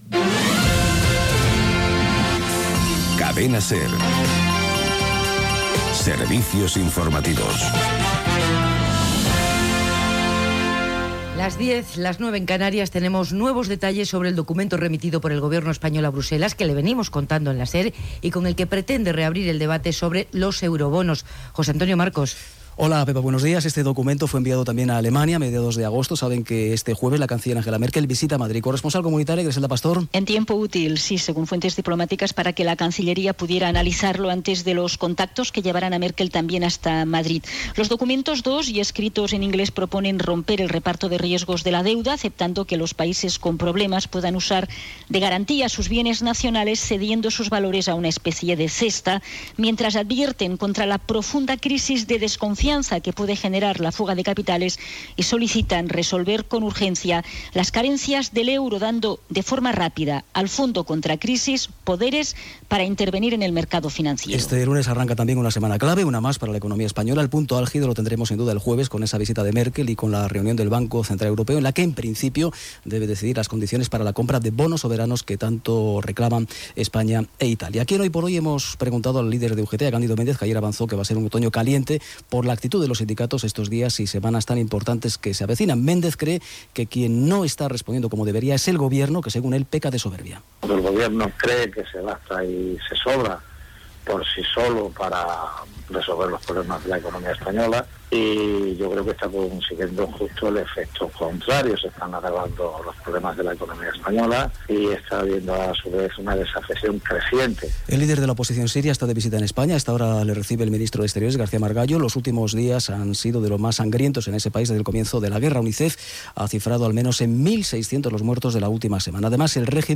Info-entreteniment
Primer dia que es fa servir aquesta versió instrumental de la sintonia de la cadena SER per als serveis informatius.
Primera edició del programa presetada per Pepa Bueno.